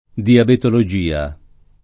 diabetologia [ diabetolo J& a ]